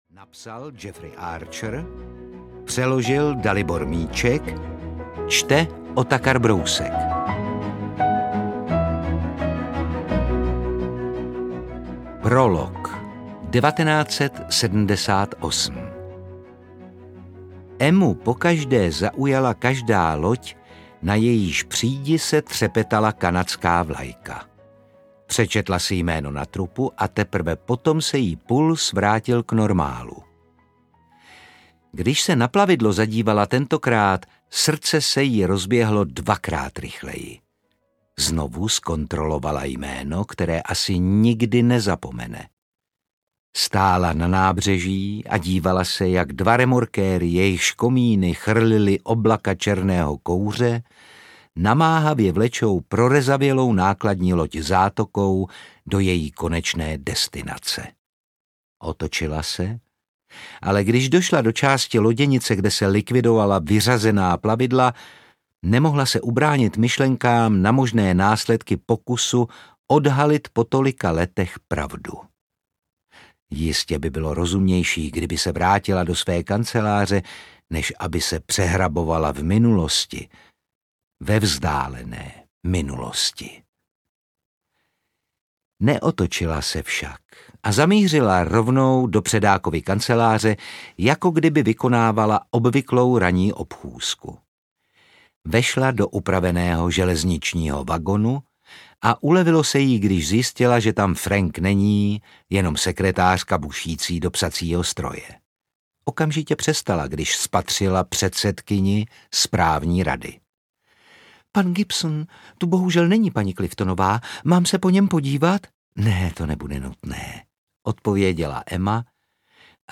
Výjimečný člověk audiokniha
Ukázka z knihy
• InterpretOtakar Brousek ml.